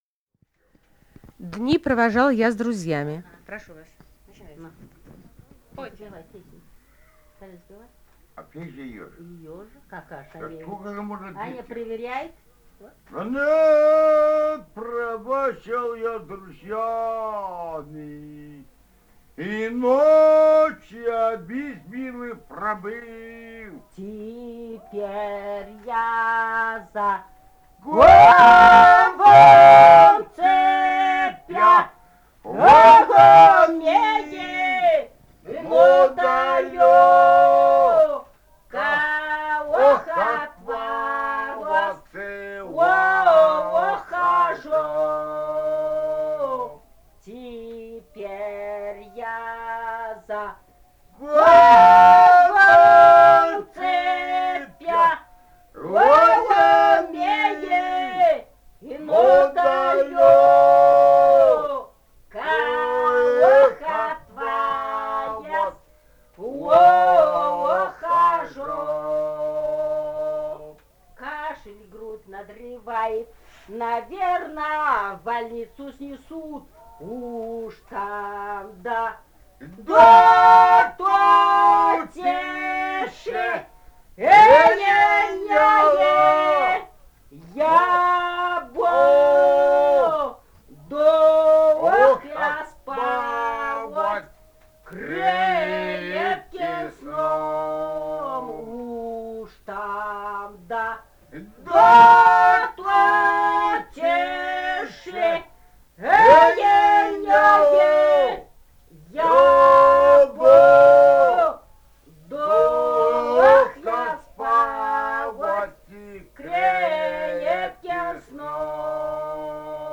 Бурятия, с. Харацай Закаменского района, 1966 г. И0905-03